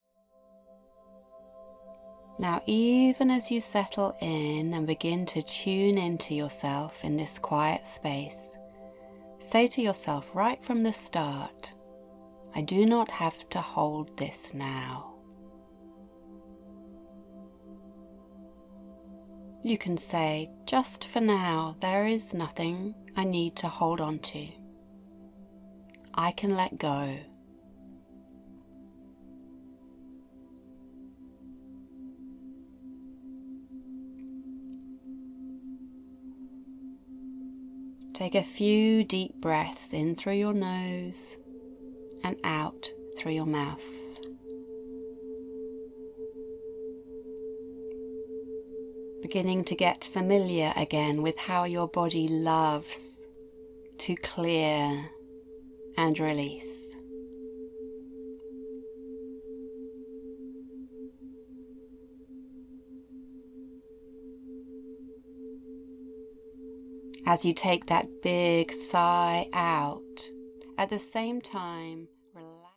A lovely meditation for releasing emotional and physical tension.